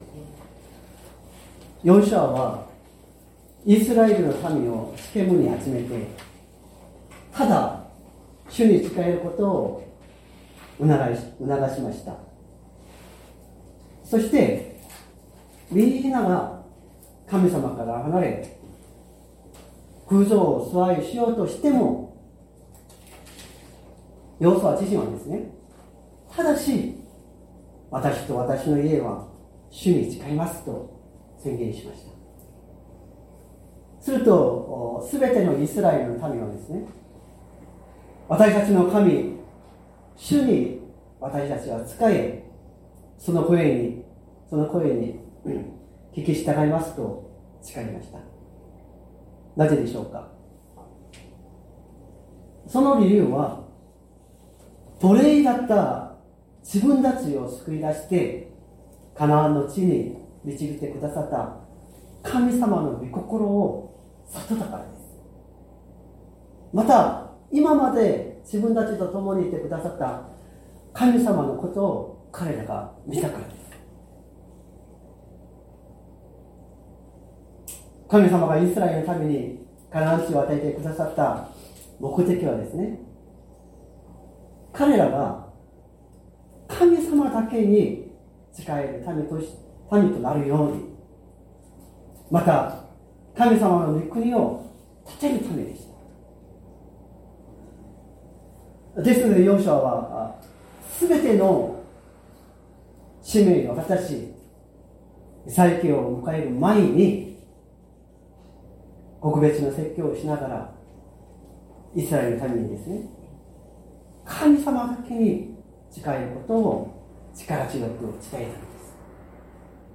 礼拝説教を録音した音声ファイルを公開しています。